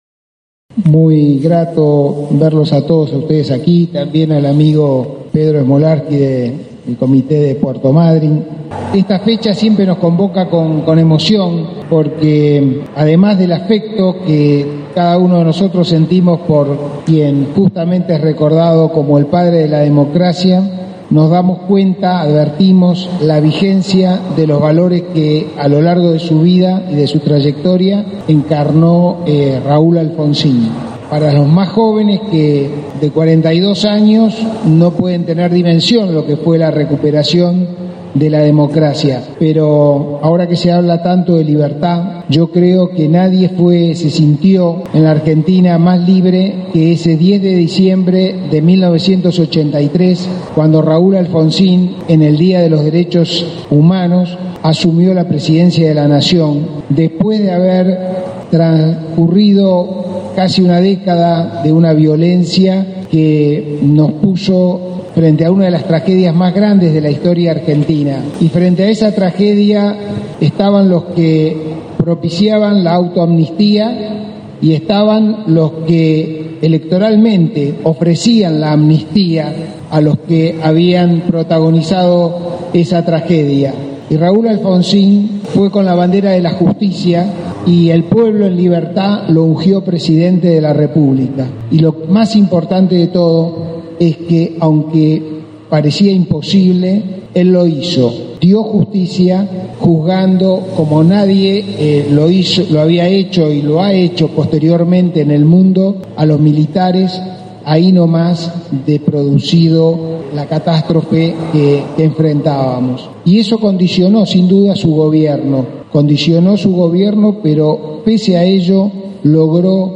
La actividad consistió en una concentración en la Plazoleta “Raúl Alfonsín” en Alvear entre Molinari y Antártida Argentina.
Luego tomó el micrófono Rubén Álvarez, actual presidente del Honorable Concejo Deliberante de Esquel, haciendo un llamado a defender las instituciones.